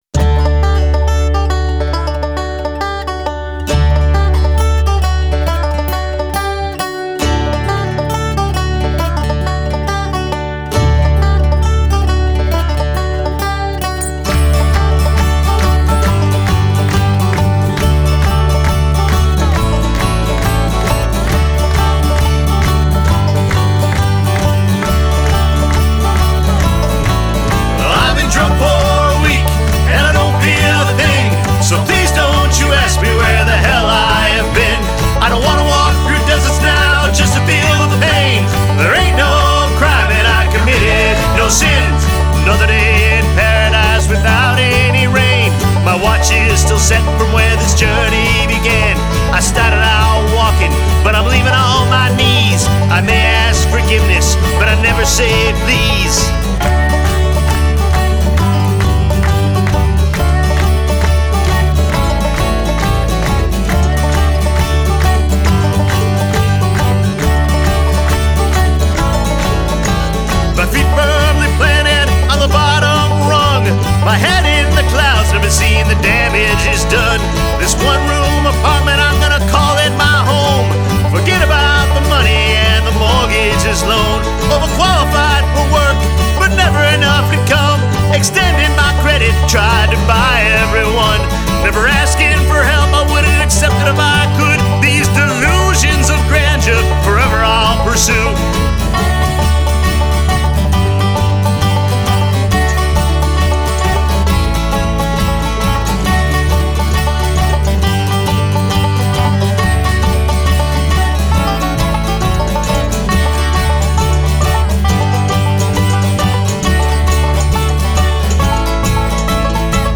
Genre: Americana.